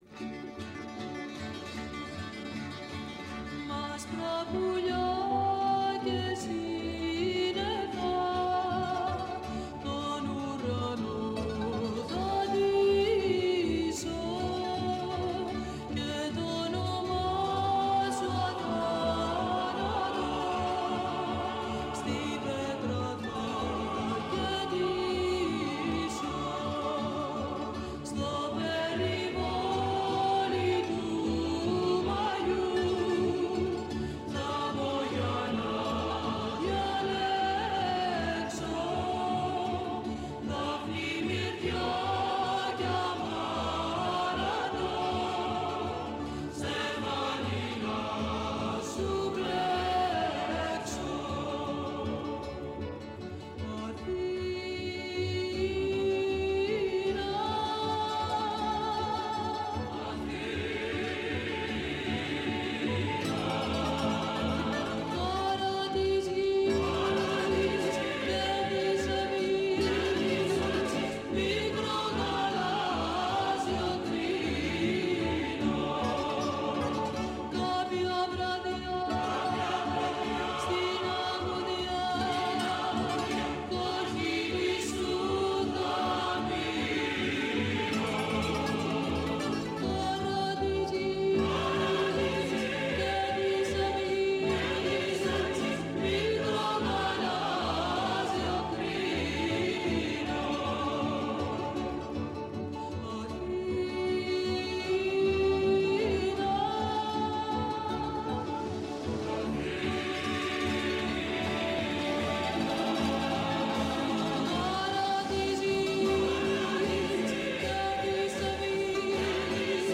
Αφορμή για αυτή τη ραδιοφωνική συνάντηση